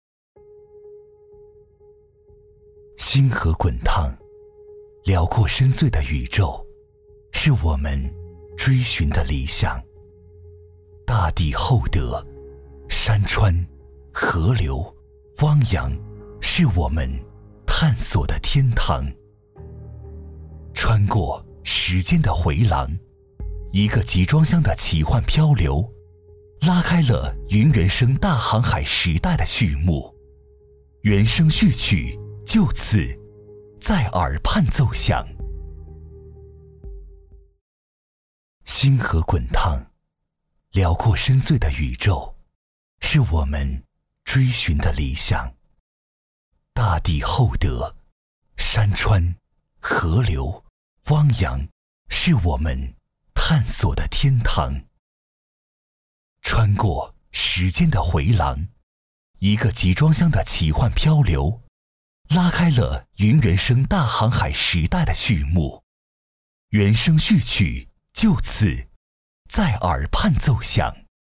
226男-年轻自然 走心
特点：年轻自然 走心旁白 GM动画
风格:亲切配音